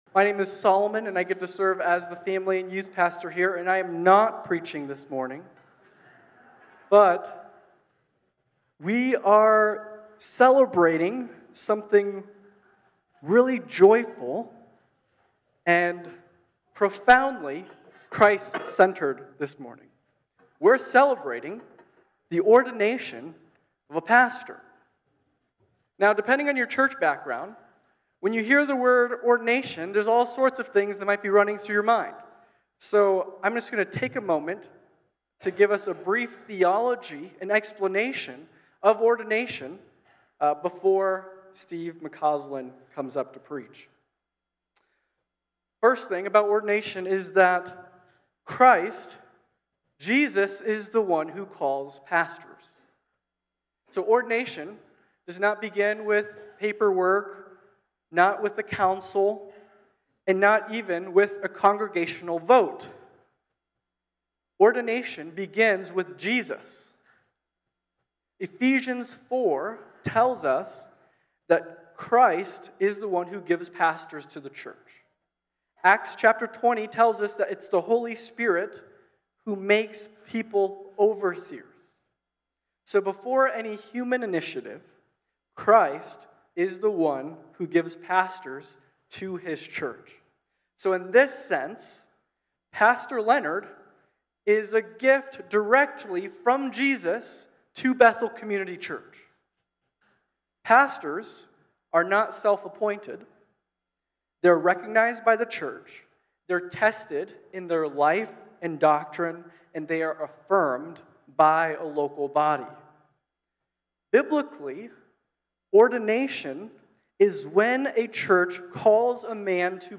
Series: Ordination Service
Service Type: Worship Gathering